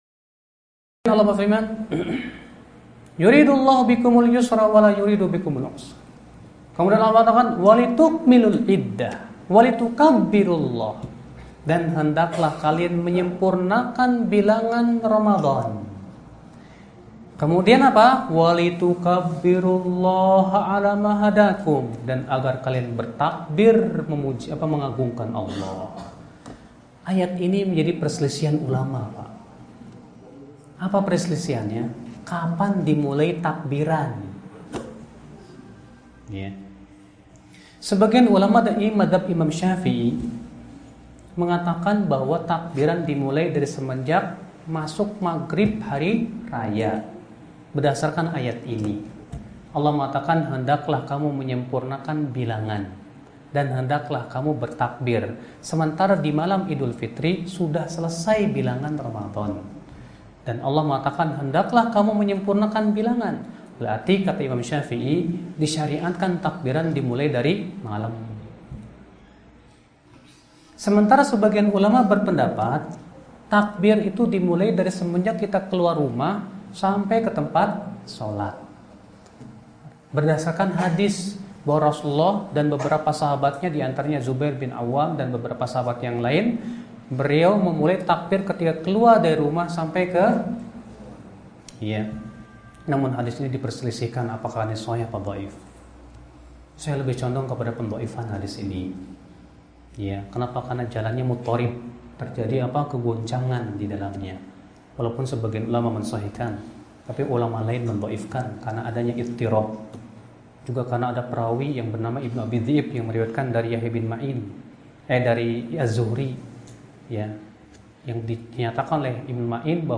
Dari Kajian Ayat-Ayat Puasa, 27 Mei 2016 di Masjid Nur Arifin, Prabumulih, Sumatera Selatan.